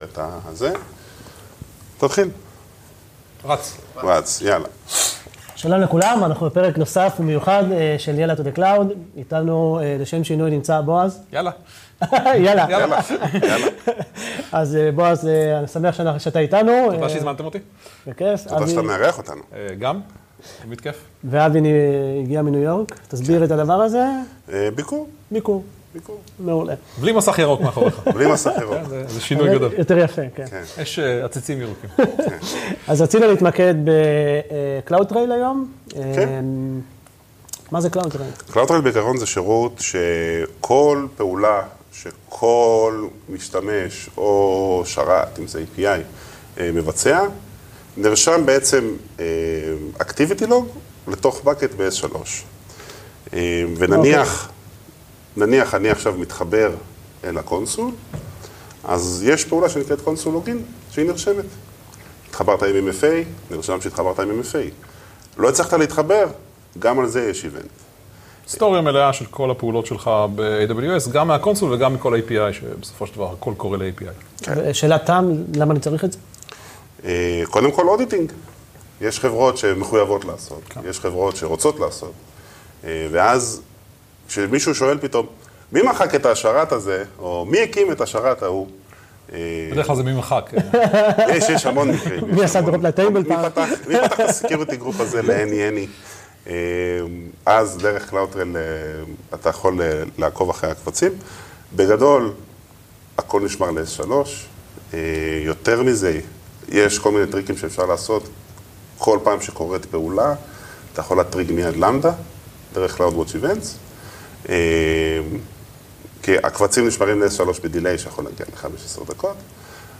הפעם, התארחנו במשרדי AWS